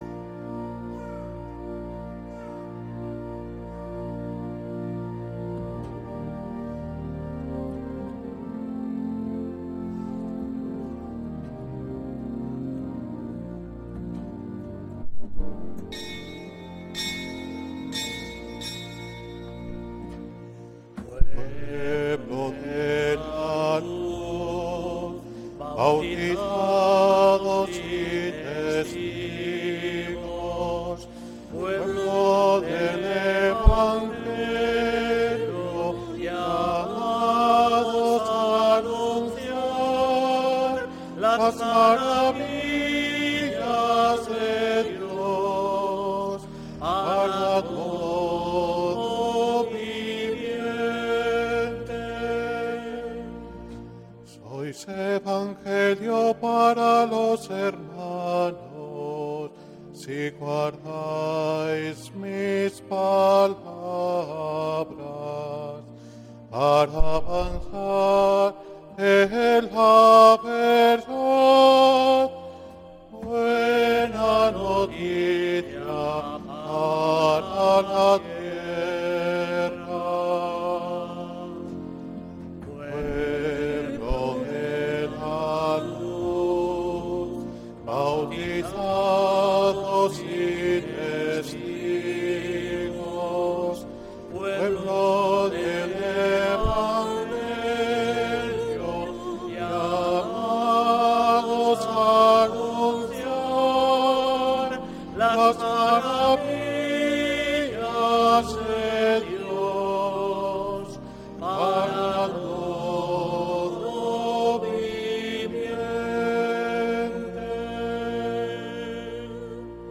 Santa Misa desde San Felicísimo en Deusto, domingo 31de agosto de 2025